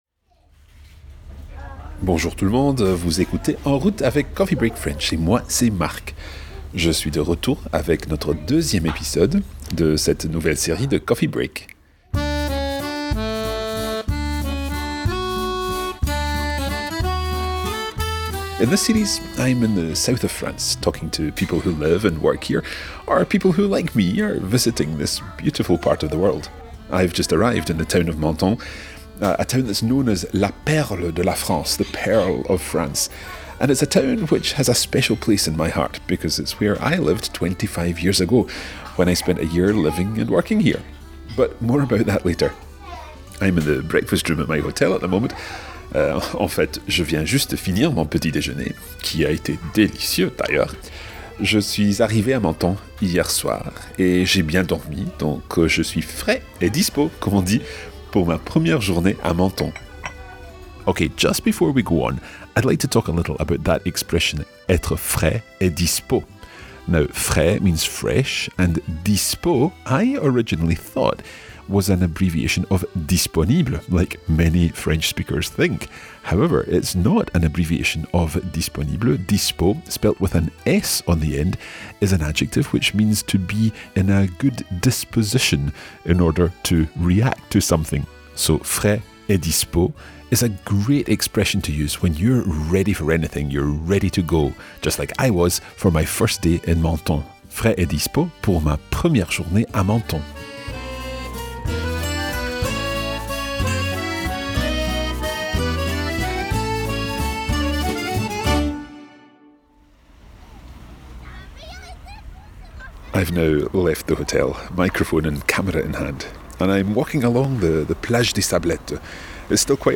Take your French on the road with the Coffee Break Team